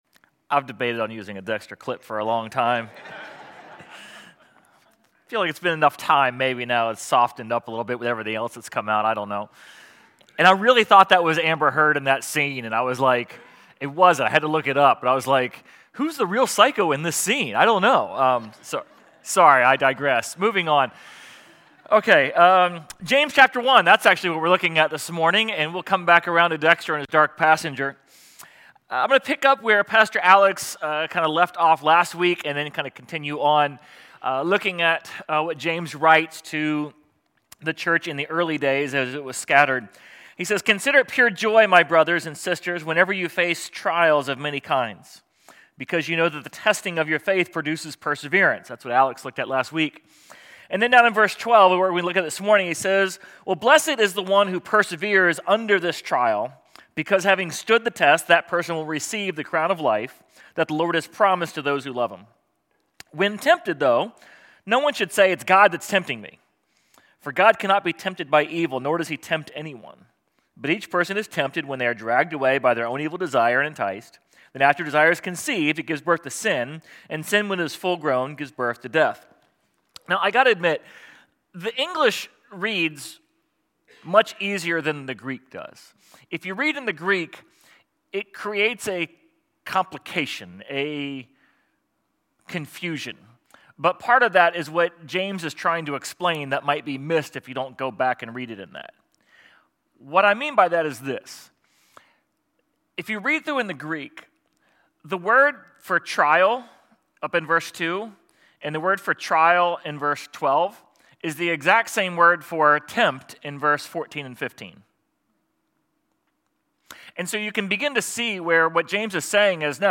Sermon_6.1.25.mp3